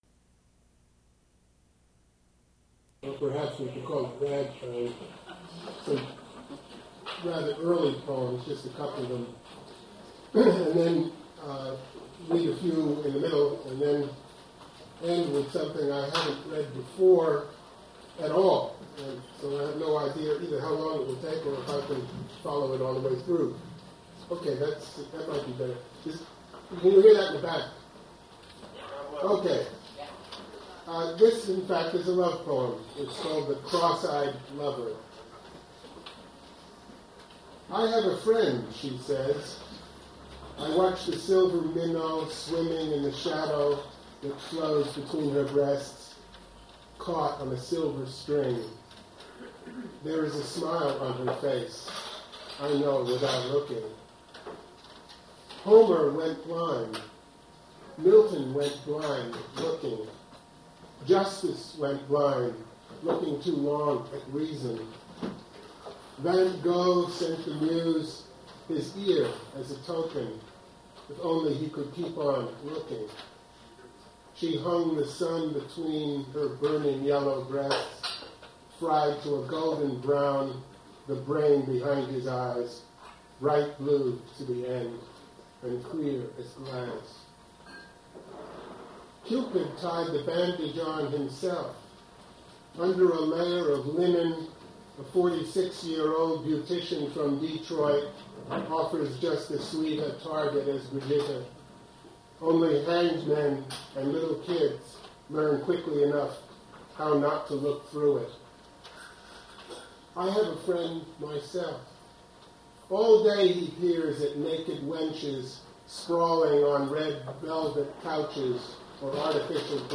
Poetry reading featuring Donald Finkel
Attributes Attribute Name Values Description Donald Finkel poetry reading at Duff's Restaurant.
mp3 edited access file was created from unedited access file which was sourced from preservation WAV file that was generated from original audio cassette. Language English Identifier CASS.748 Series River Styx at Duff's River Styx Archive (MSS127), 1973-2001 Note incomplete recording; starts in the middle of the performance and ends abruptly.